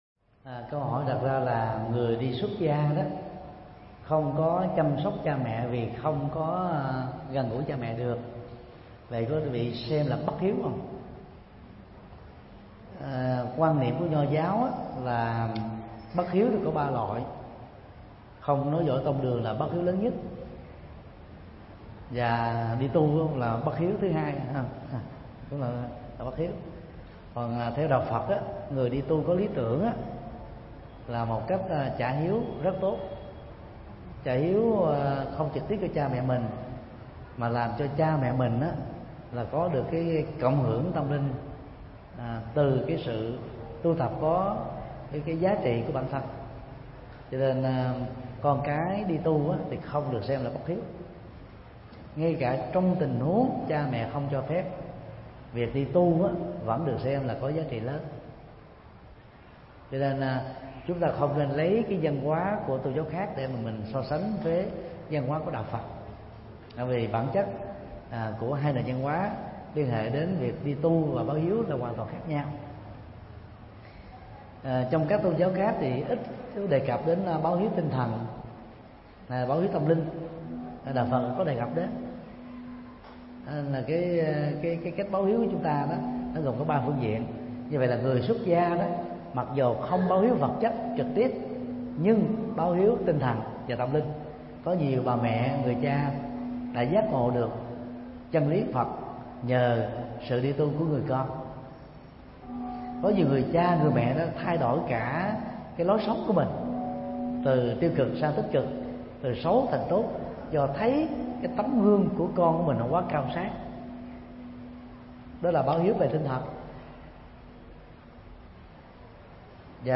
Vấn đáp: Hiếu hạnh của người xuất gia – Thầy Thích Nhật Từ mp3